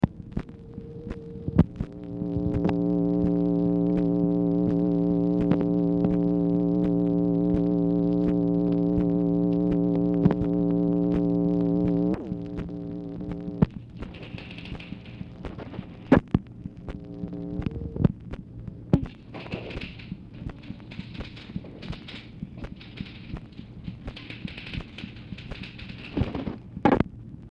OFFICE NOISE
Format Dictation belt
Oval Office or unknown location